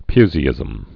(pyzē-ĭzəm, pysē-)